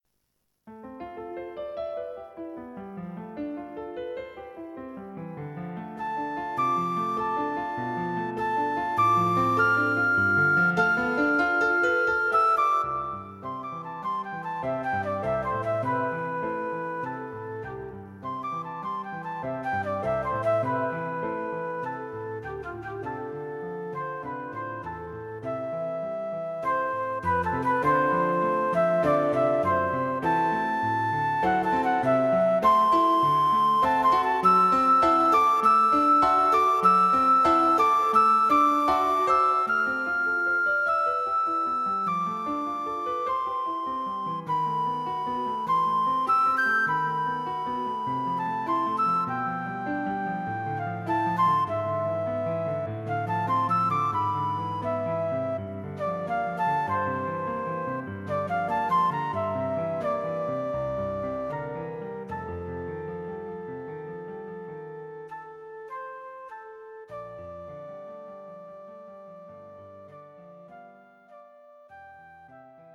Flute and Piano
This famous melody is arranged here for Flute and Piano.